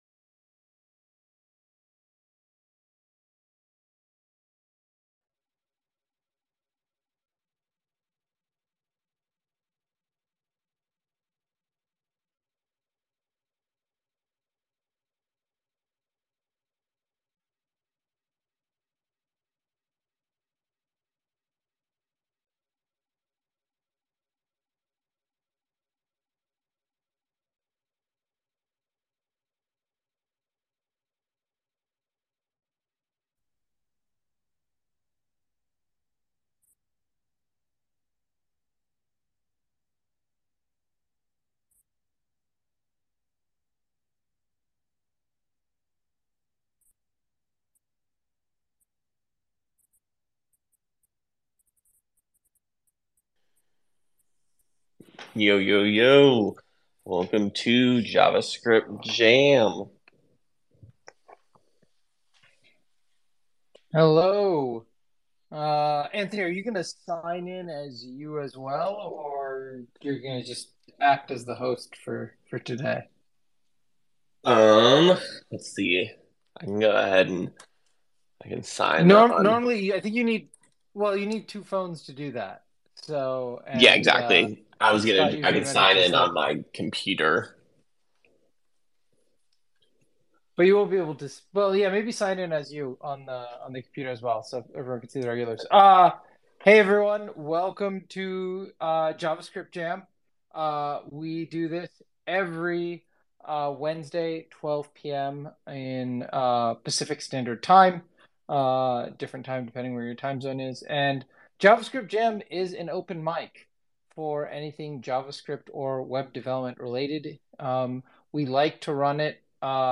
An open mic covering SolidStart updates, RedwoodJS developments, Deno’s Fresh 1.4 release, and tips for learning new frameworks